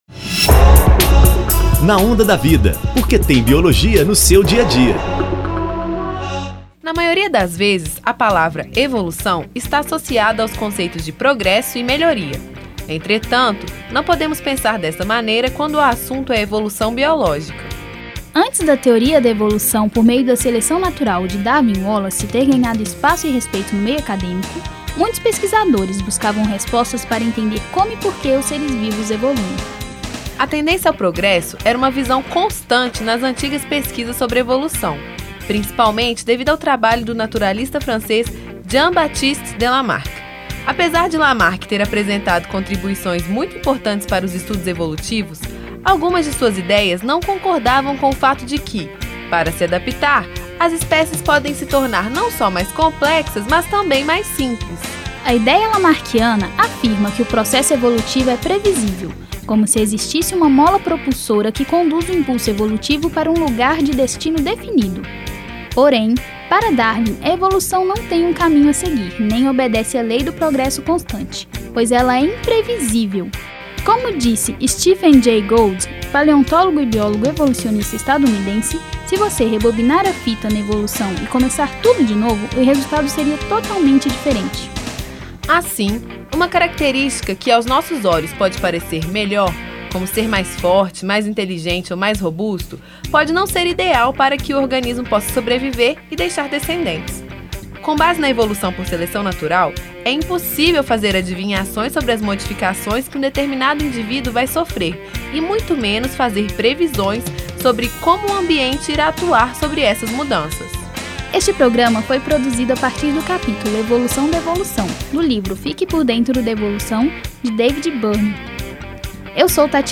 Vozes